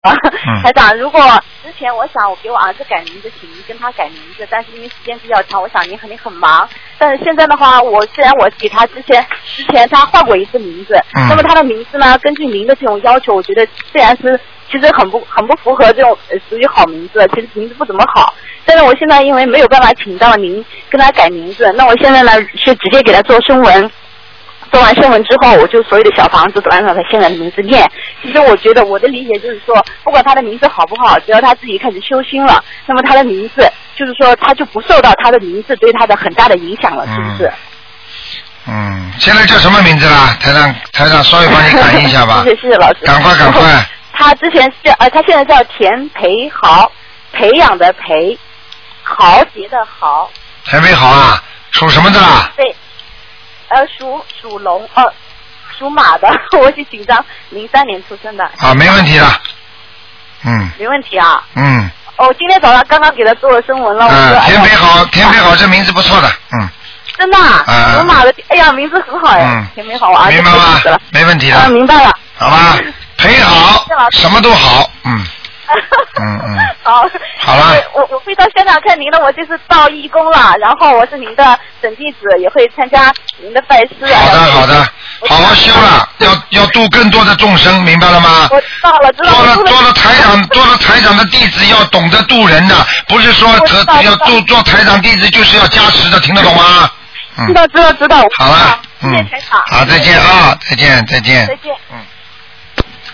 目录：2012年03月_剪辑电台节目录音集锦